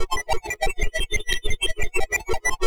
Synth 28.wav